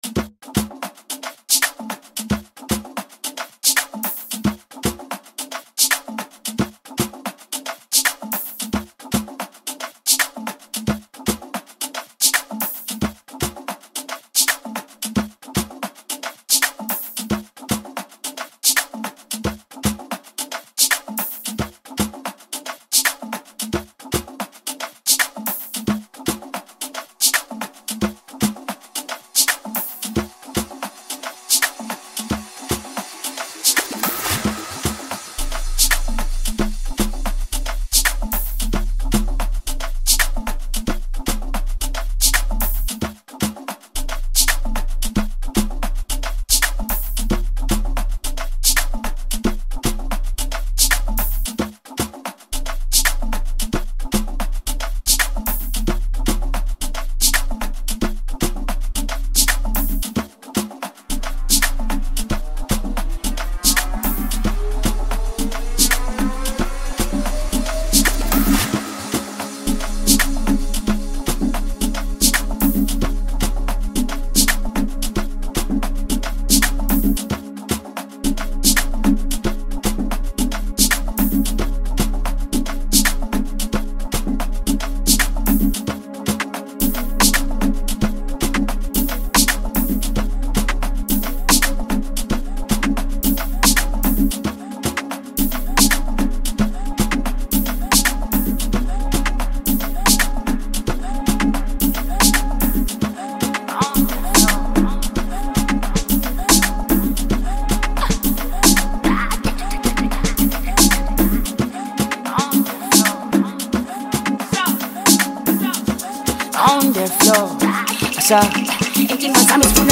talented South African singer
heartwarming recording